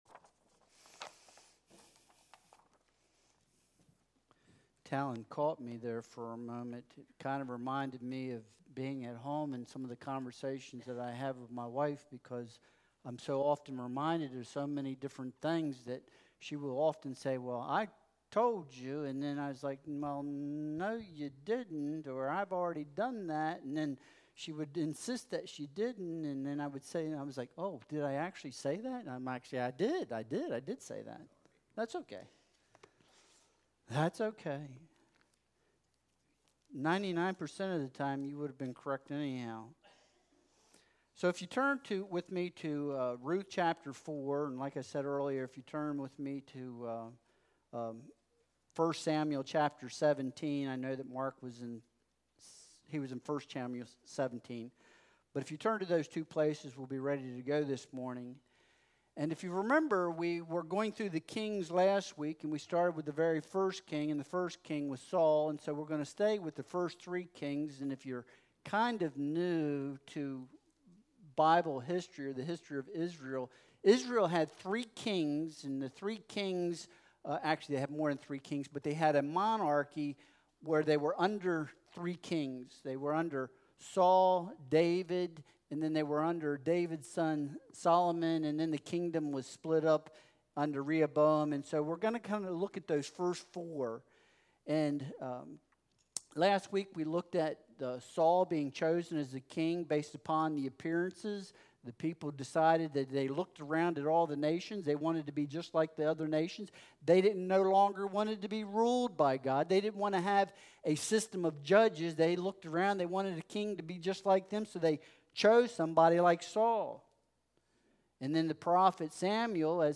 Ruth 4.13-22 Service Type: Sunday Worship Service Download Files Bulletin « Solomon